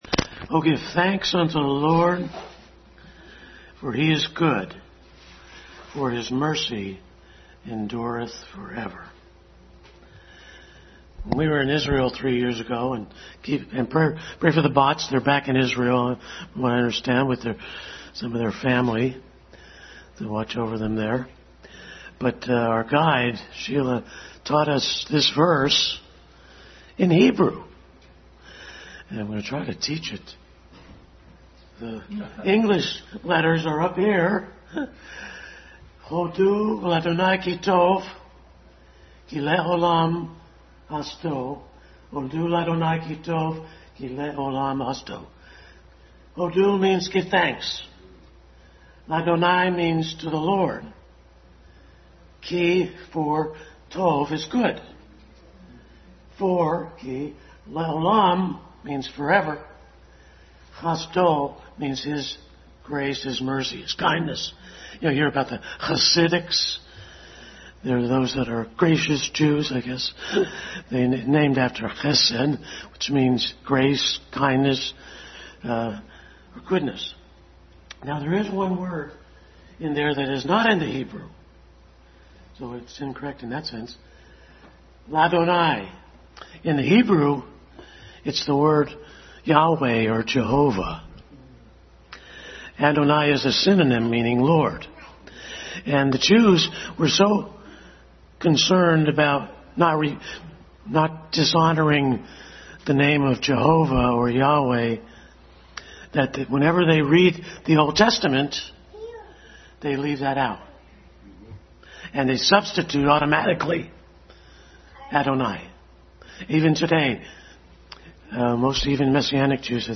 Psalm 107 Passage: Psalm 107 Service Type: Family Bible Hour Family Bible Hour message.